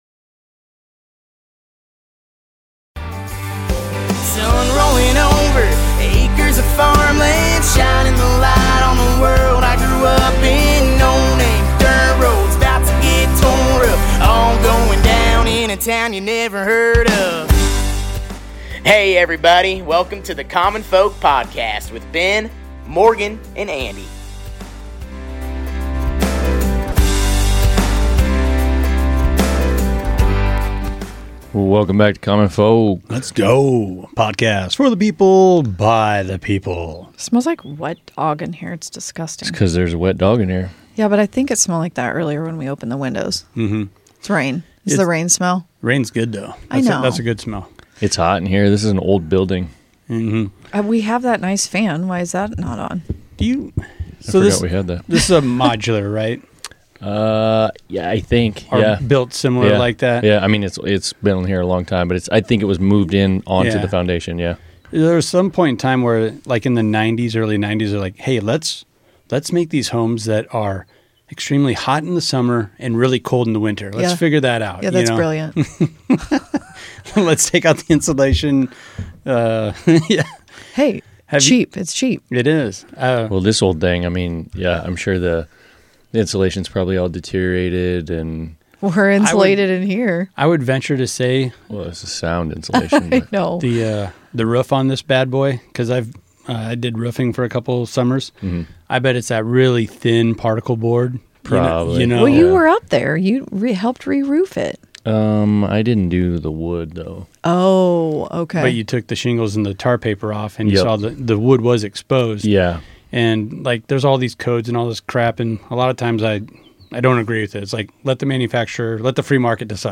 In this episode of Common Folk, we take you on-location to a small-town microbrewery where passion meets perseverance. Join us as we sit down with a local brewer who's betting on his dream, diving headfirst into the craft beer scene—a niche some say has lost its novelty. From the clink of glasses to the hum of fermenters, experience the sights and sounds of a brewery that's more than just a business; it's a labor of love.